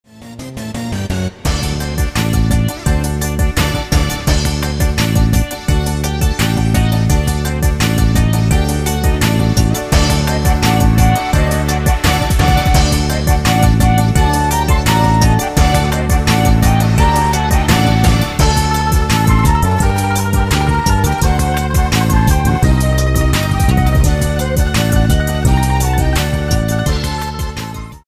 Folk music- instrumental music